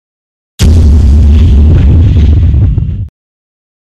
Âm thanh Đối đầu, Versus (V/S), Bắt đầu Chiến đấu
Thể loại: Đánh nhau, vũ khí
Description: Hiệu ứng âm thanh âm thanh Đối đầu, Versus (V/S), bắt đầu chiến đấu, bắt đầu trận chiến, bắt đầu cuộc thi đối kháng trực tiếp giữa 2 người rất căng thẳng, Versus (V/S) sound effect. Âm thanh này thường nghe thấy rất nhiều trong game đối kháng, đánh nhau, cuộc thi trả lời câu hỏi.
am-thanh-doi-dau-versus-v-s-bat-dau-chien-dau-www_tiengdong_com.mp3